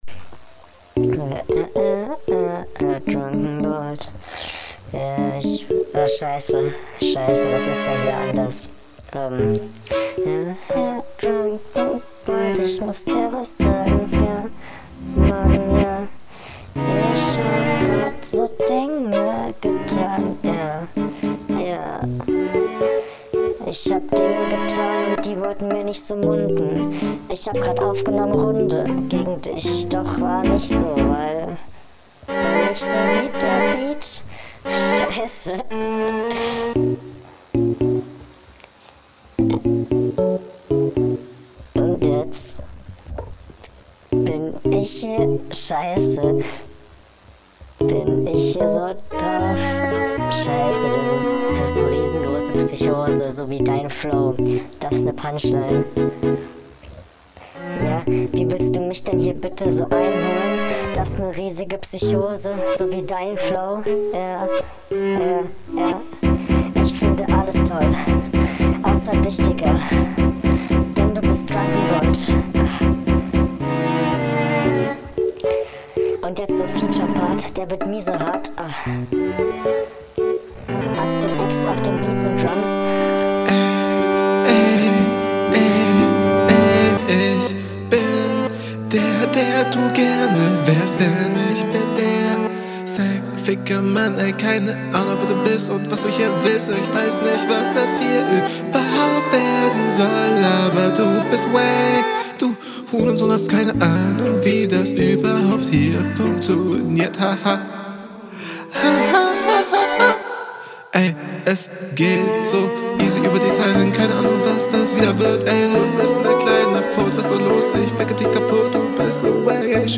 Das Intro ist unnötig, unwitzig und unnötig lang.. denkt man sich, bevor man merkt, dass …